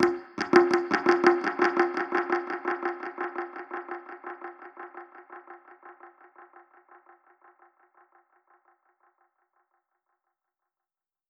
DPFX_PercHit_B_85-01.wav